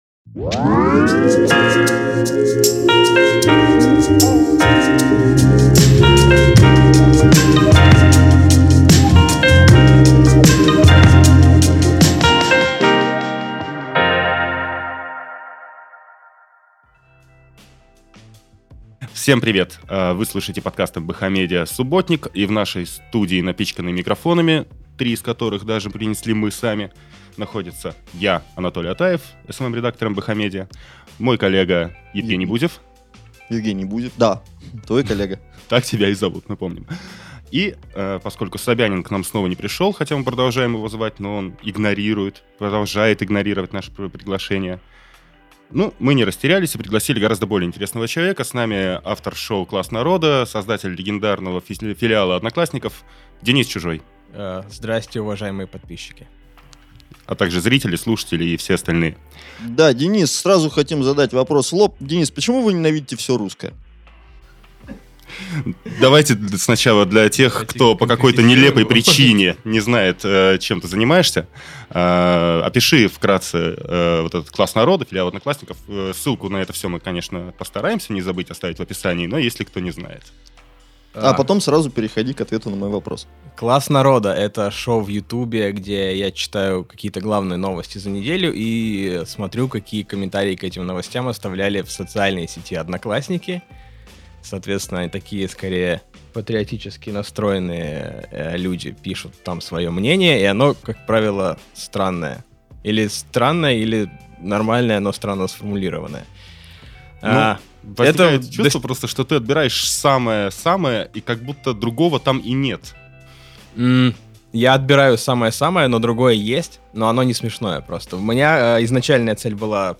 Гость нового «Субботника» — стендап-комик Денис Чужой. Он рассказал, почему не может не любить русских, как пенсионная реформа изменила контент «Одноклассников» и вспомнил о том, как «нашисты» вербовали молодёжь. Рэп-фестиваль Дмитрия Киселева, сотни тысяч ликующих людей на праздновании Дня российского флага, курский губернатор и бесплатные похороны — в этом выпуске.